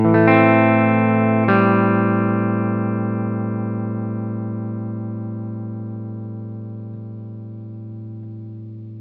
Strum the E minor and try taking off your finger to create the Em7 whilst keeping the chord ringing, the changing note we get is E to D.
Here is an audio example of strumming the Em chord and alternating between the E (tonic) and D (7th).
A-Am7